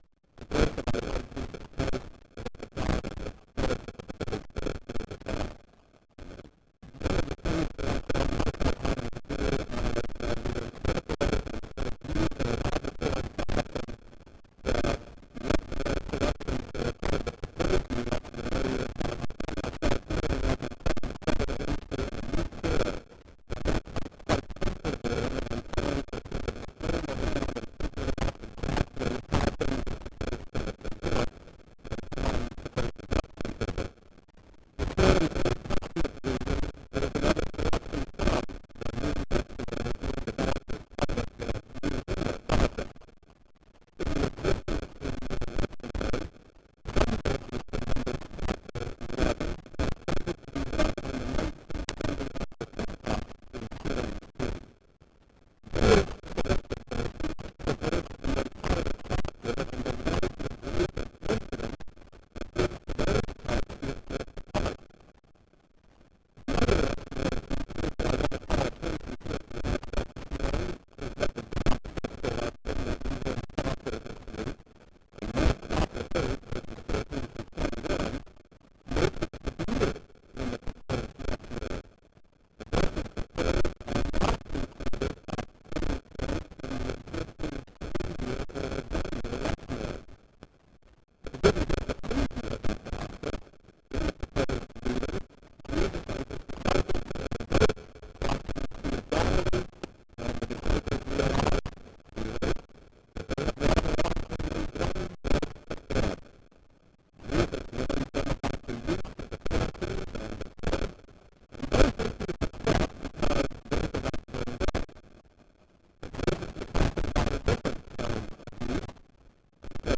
Chapel Sermons Academic Year 2002-2003 | Chapel Sermons | Concordia Seminary - Saint Louis